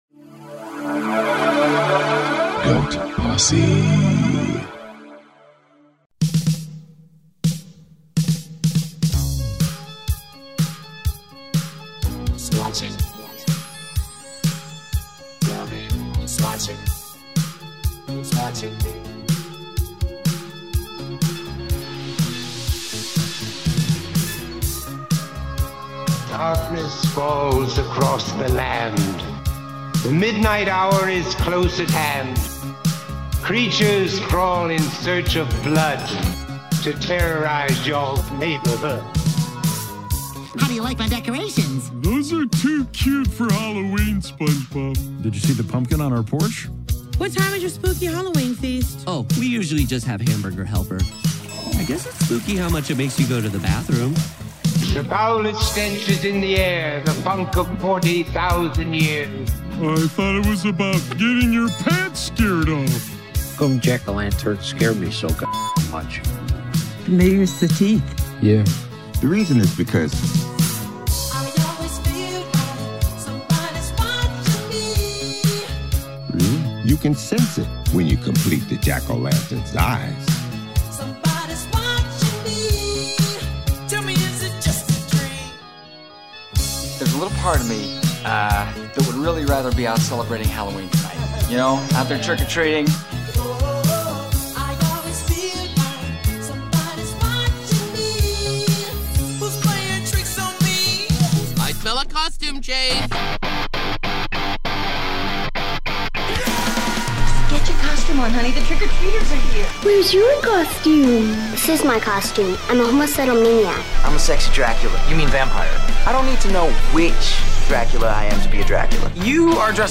Hear the yearly Trivia Intro as it was birthed in the studio, before critical ears could cast their aspersions.